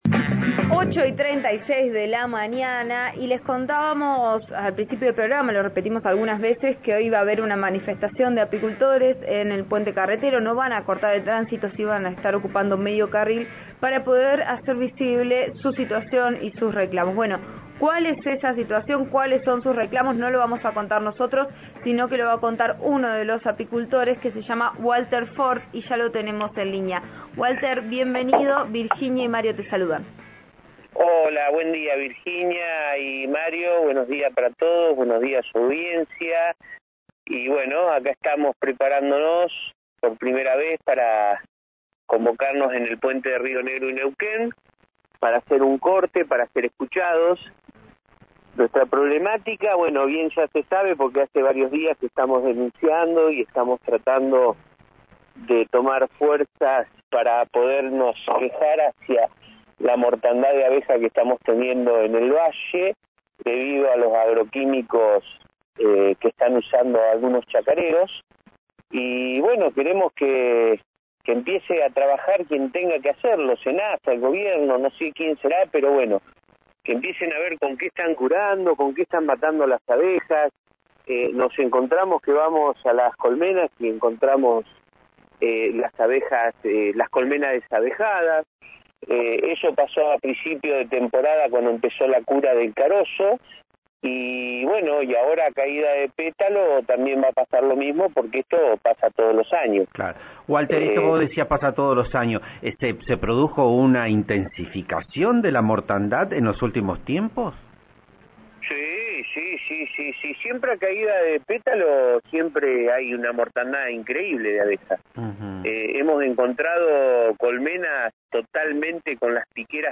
Video: apicultores protestaron en el puente carretero en rechazo a un arancel en Río Negro - Diario Río Negro
Apicultores se plantaron en la Ruta Nacional 22, en el puente que une Cipolletti con Neuquén en defensa de la vida de las abejas y contra las fumigaciones, por el libre tránsito de colmenas y el rechazo a los cánones interprovinciales.